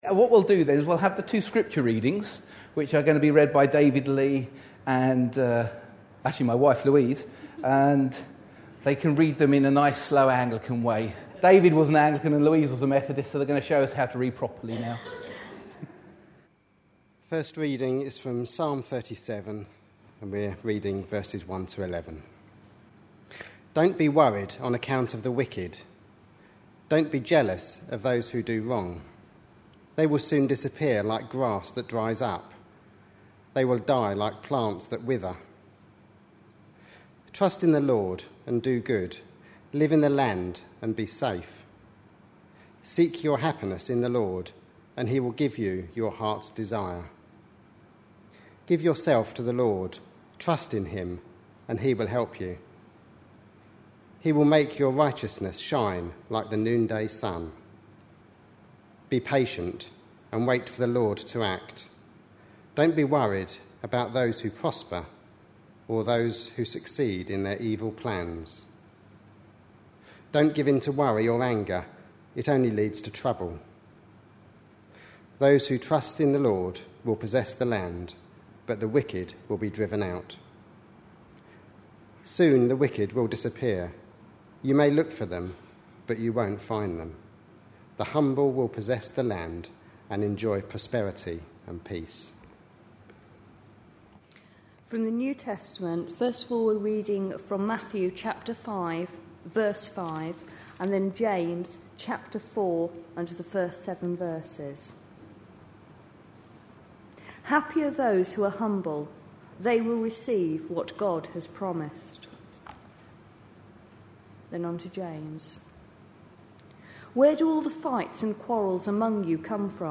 A sermon preached on 2nd October, 2011, as part of our The Beatitudes. series.